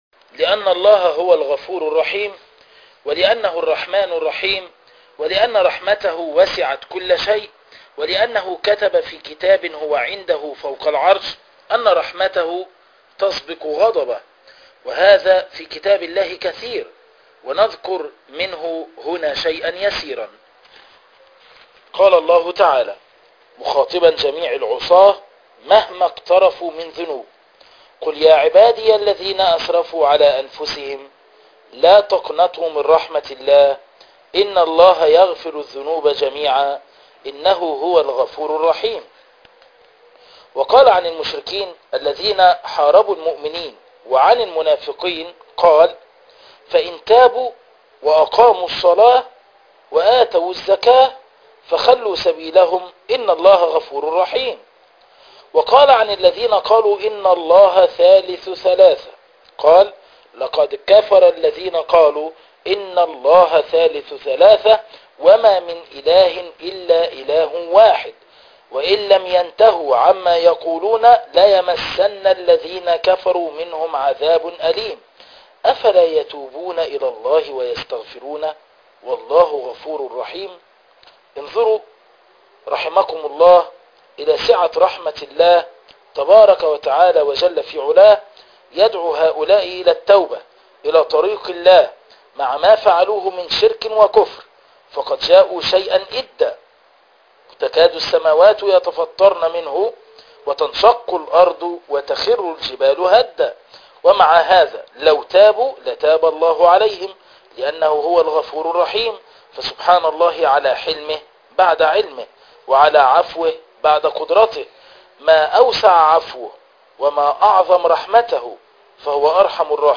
الدرس السادس - فوائد من قصة أصحاب الأخدود